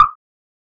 Pharrell Pitch Click.wav